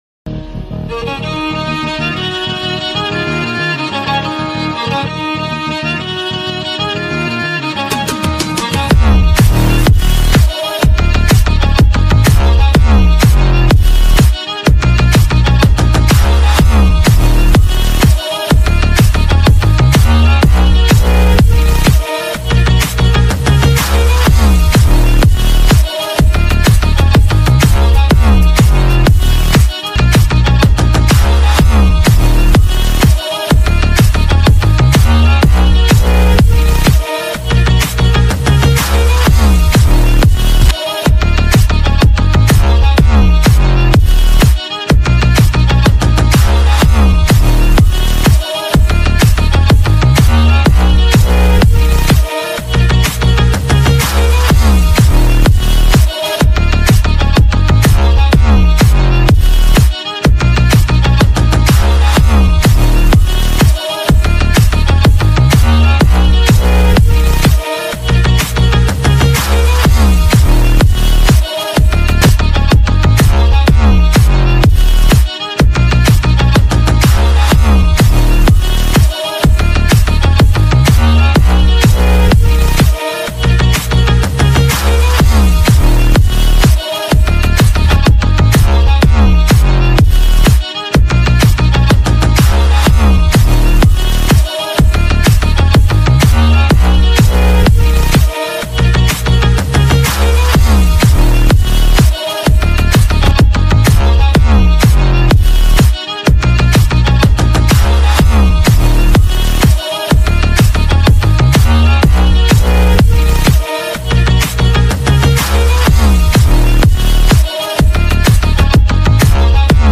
ریمیکس تقویت بیس قوی سیستمی